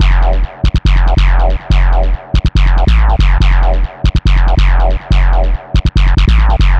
BL 141-BPM F.wav